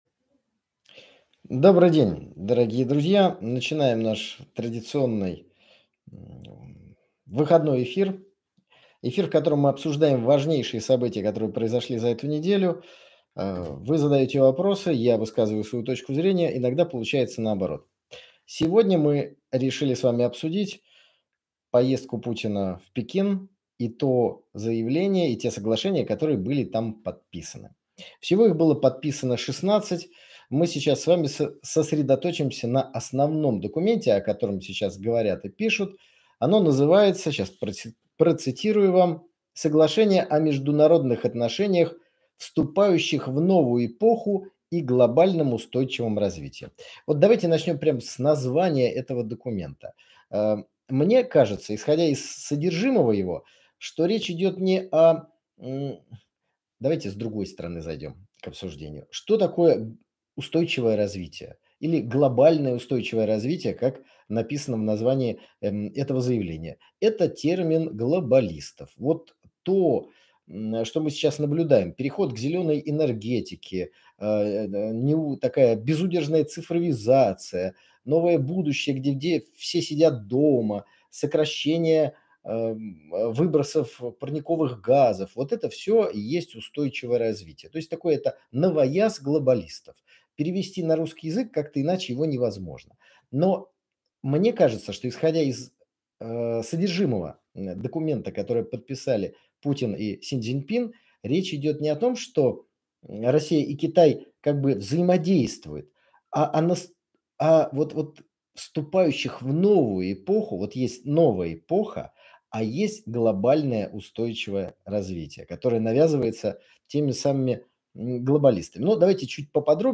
В очередном еженедельном прямом эфире поговорили о новом договоре России и Китая, обсудили переговоры наших лидеров Путина и Си Цзиньпина, а также проанализируем как новый уровень отношений между Россией и Китаем повлияет на мировую политику.